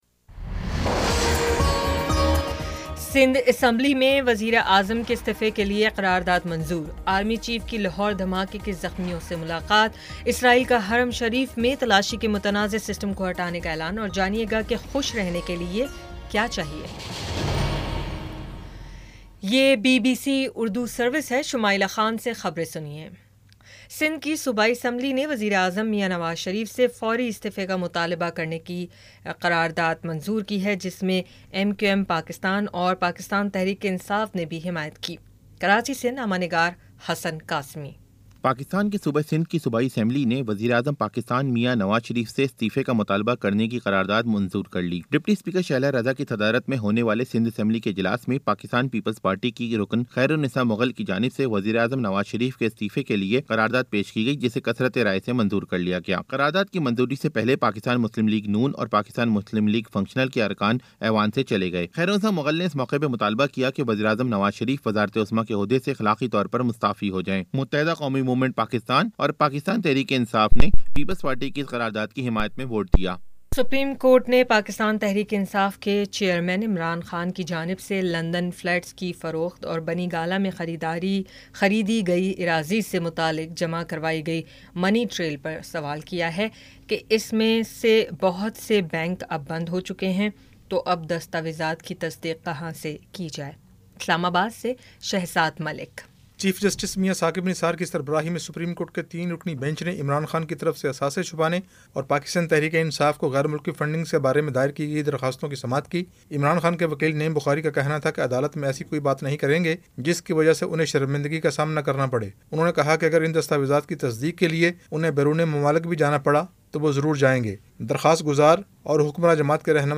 جولائی 25 : شام پانچ بجے کا نیوز بُلیٹن